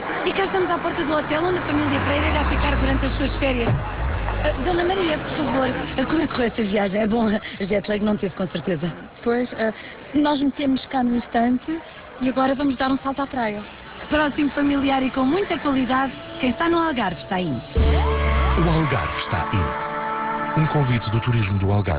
...no passado dia 12 de Julho estrearam em rádio 3 spots de uma nova campanha do Turismo do Algarve intitulada "o Algarve está in". Estes spots convidam ao turismo nesta região de Portugal numa época de férias.